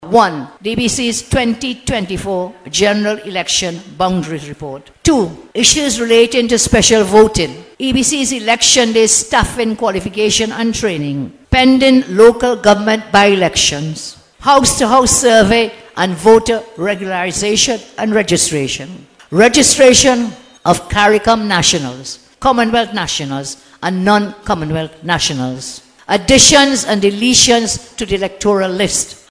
This announcement came last night from UNC Leader Kamla Persad-Bissessar, as she spoke during a cottage meeting in Princes Town.